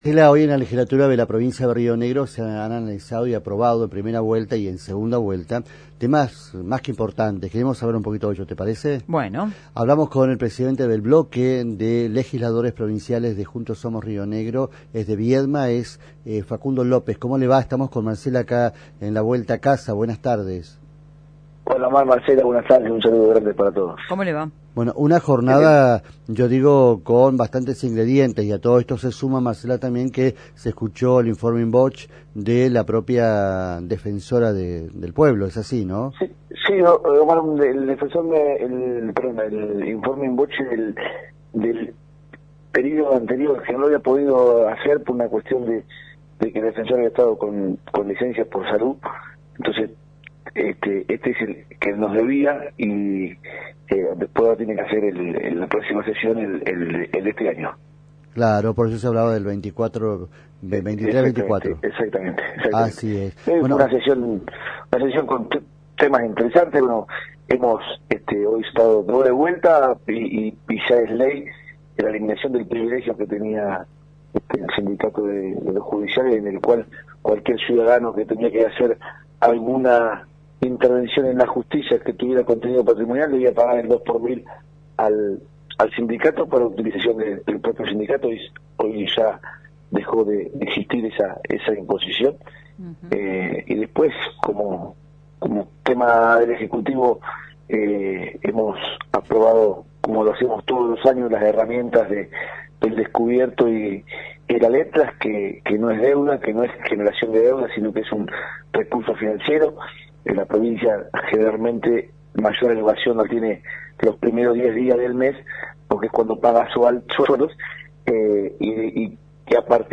En diálogo con LU19, el legislador de Juntos Somos Río Negro, Facundo López, repasó los proyectos que marcaron la sesión de este jueves en la Legislatura. Entre los puntos destacados mencionó la eliminación de la tasa judicial destinada a SITRAJUR, una medida que busca ordenar el esquema tributario y mejorar la eficiencia administrativa del Poder Judicial.